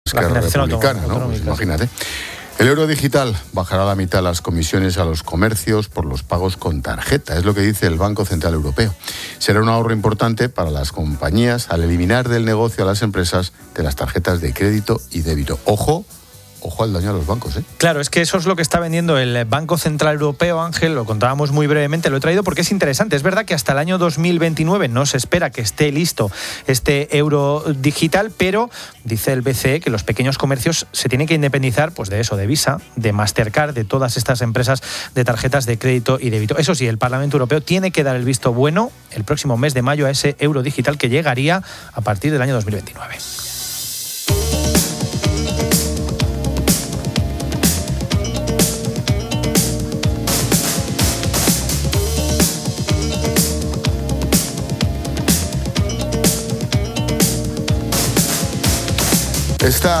Expósito aprende en Clases de Economía de La Linterna con el periodista económico